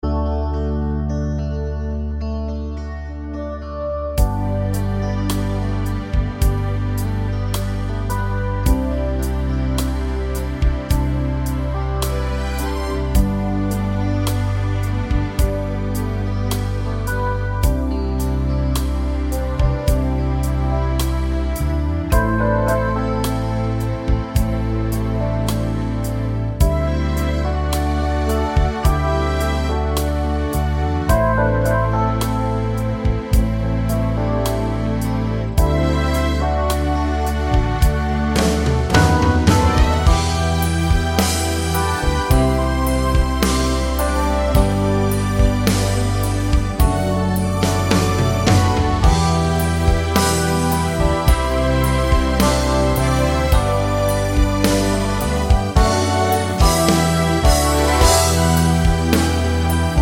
no Backing Vocals Crooners 4:33 Buy £1.50